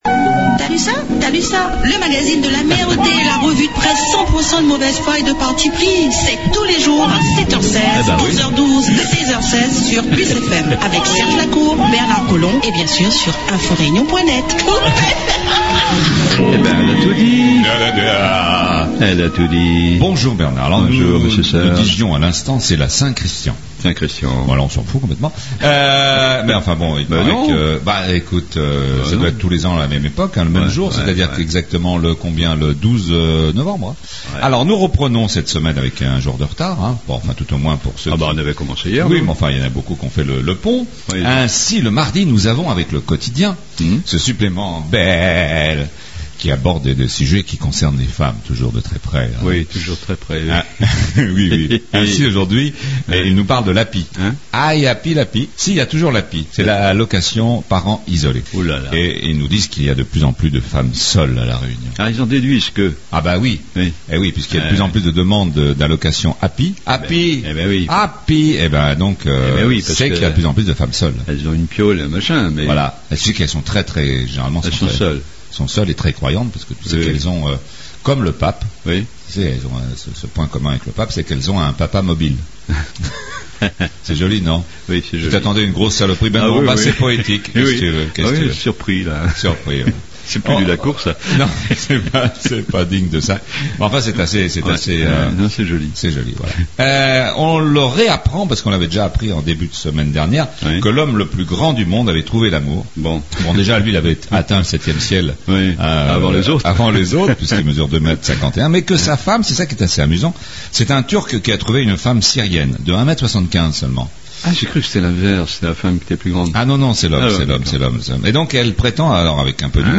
La revue de presse du jour :